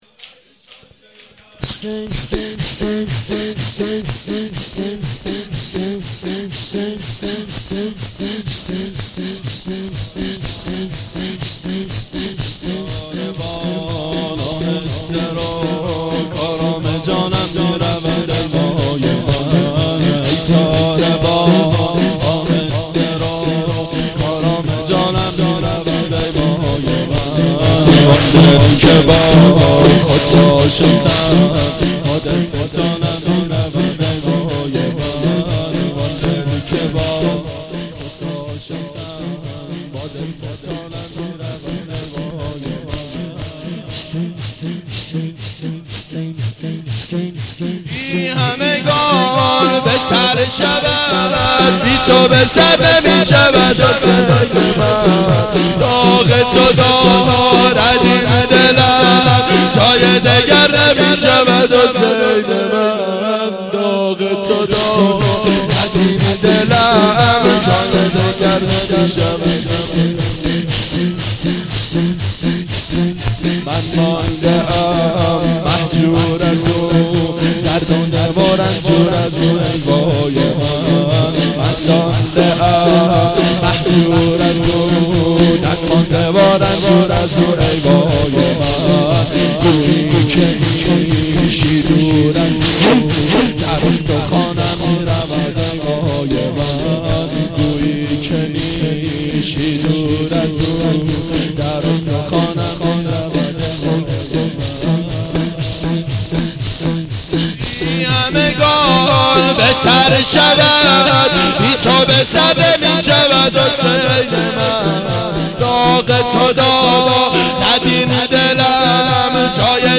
گلچین جلسات هفتگی